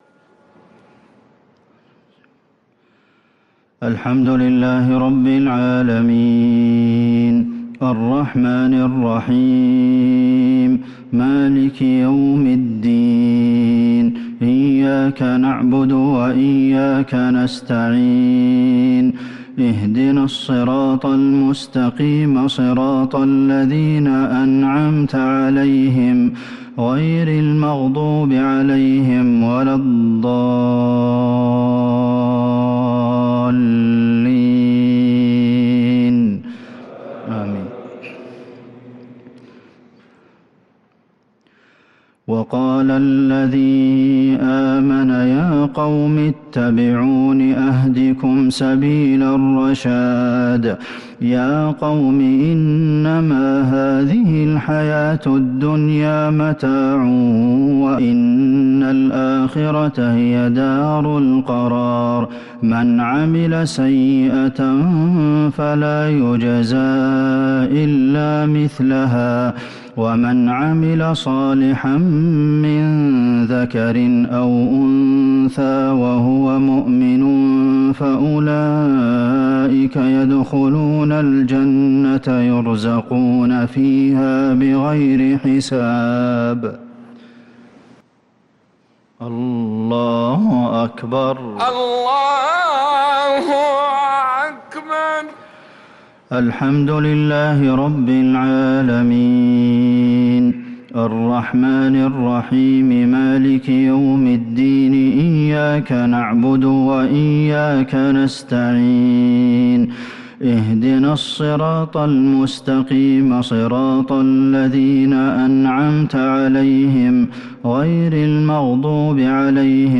صلاة المغرب للقارئ عبدالمحسن القاسم 21 شوال 1443 هـ